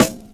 Track 02 - Snare OS 01.wav